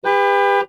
horn.wav